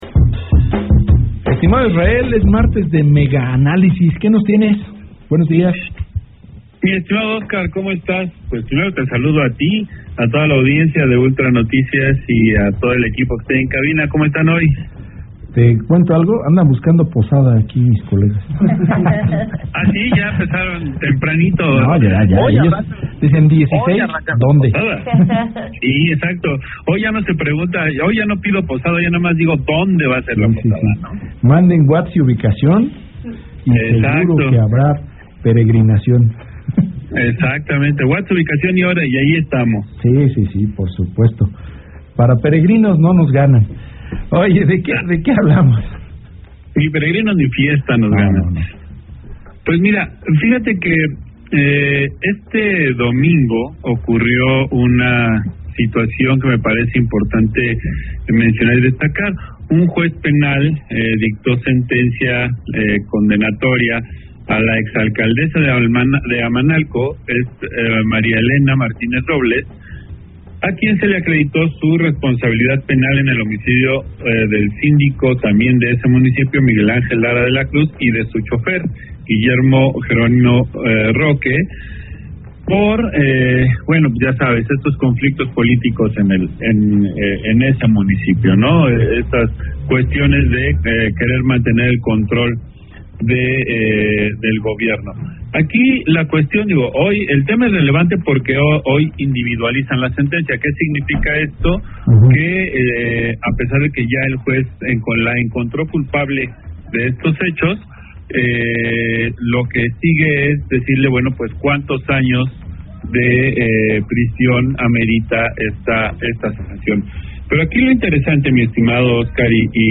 analista político